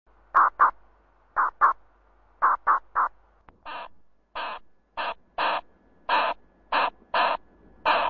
głosy innych nawałników